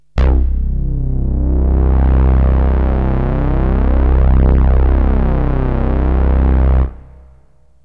SYNTH BASS-1 0004.wav